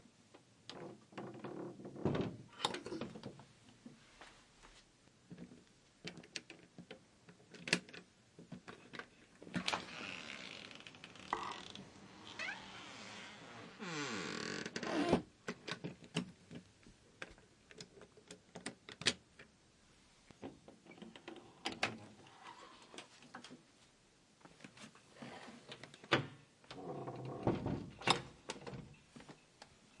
玻璃门
描述：声音的特点是一个郊区住宅的玻璃前门正在打开和关闭。门的打开需要更多的增益，这是在Pro Tools中添加的。
Tag: 关门 开门 玻璃